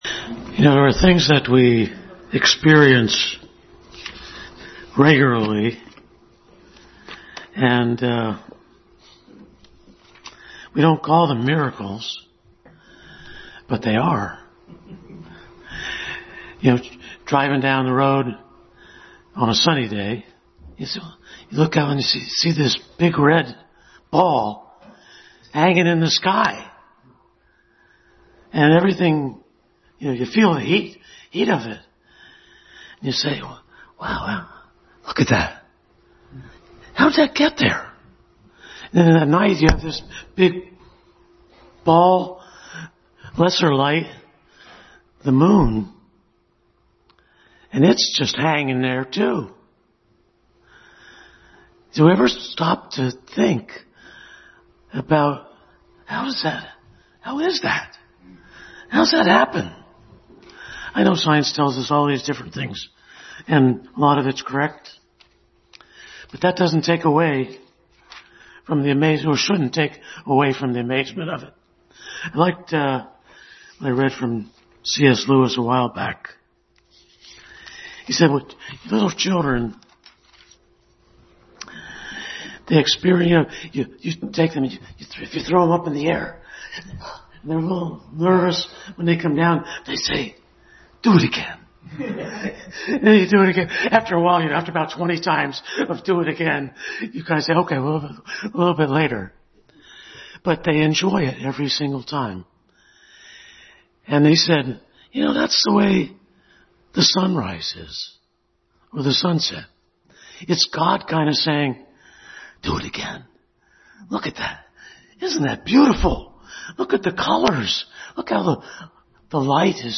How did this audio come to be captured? Passage: John 3:16 Service Type: Family Bible Hour